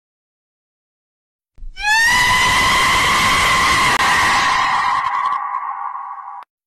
Rooster Screaming Meme - Botón de Efecto Sonoro